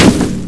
Bullet_biu.wav